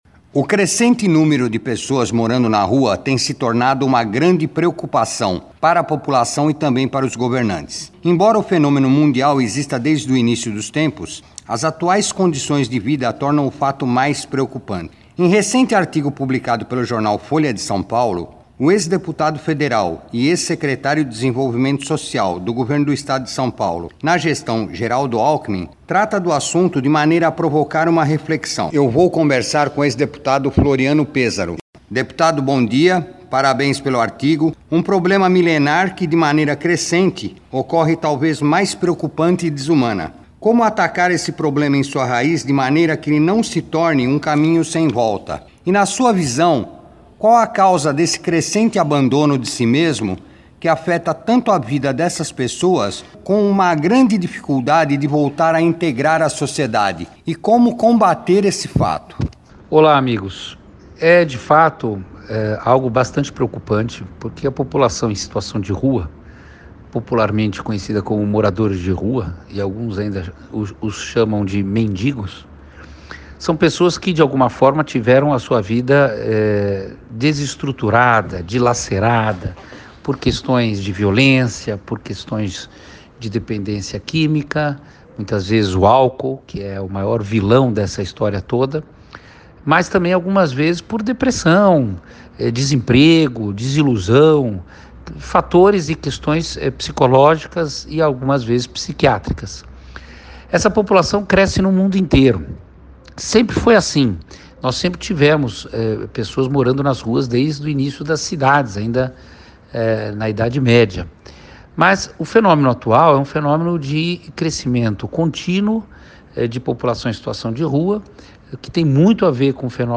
entrevista com Floriano Pêsaro